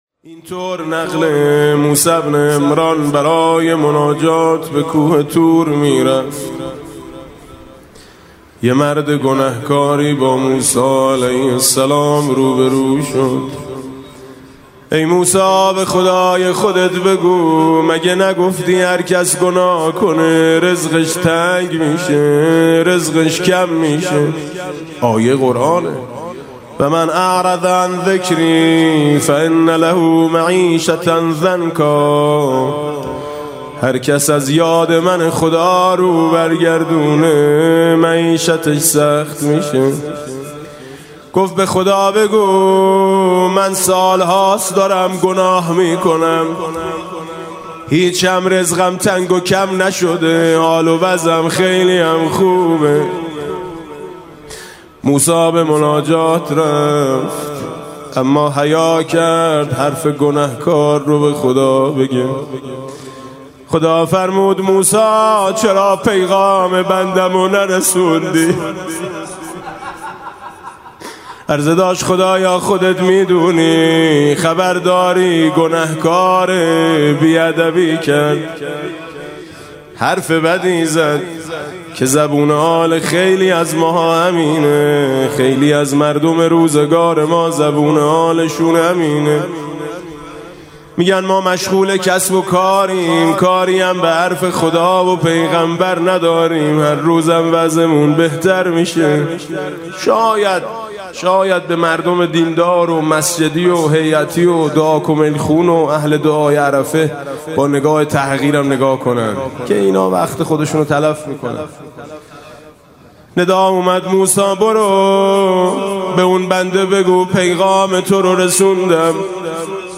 [آستان مقدس امامزاده قاضي الصابر (ع)]
مناسبت: قرائت دعای عرفه